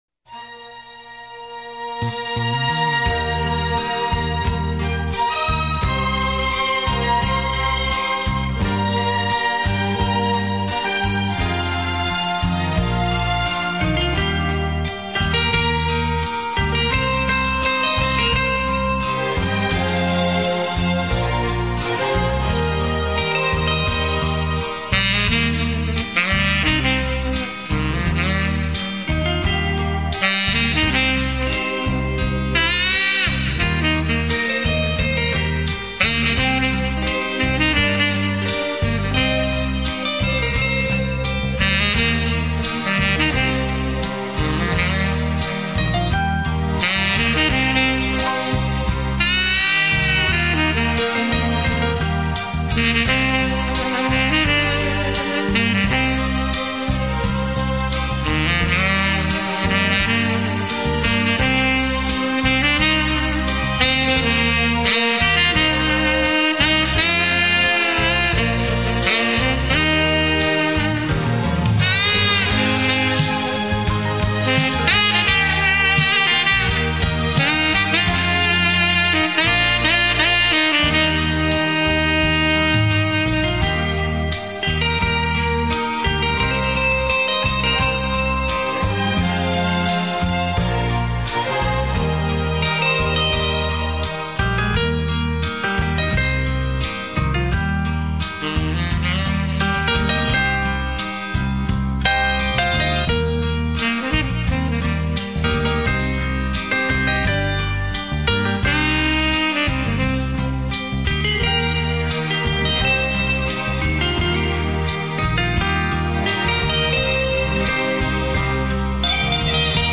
萨克斯风主奏